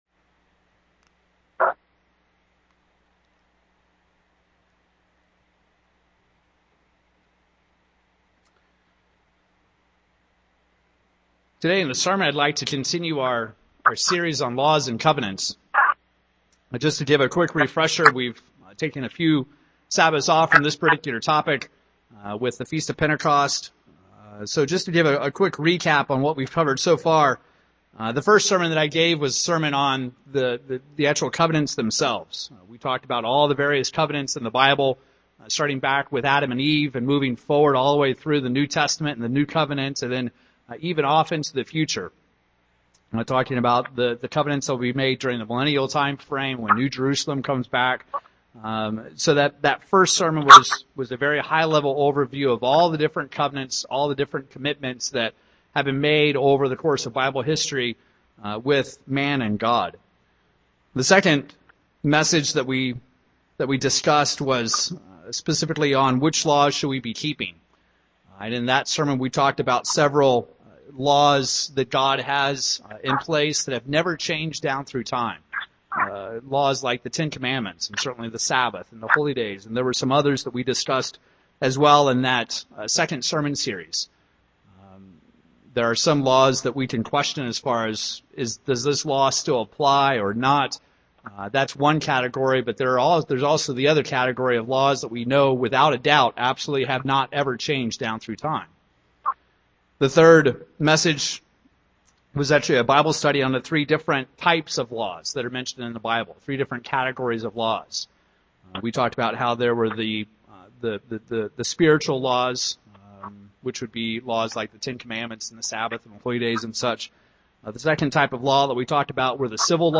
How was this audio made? Given in Wichita, KS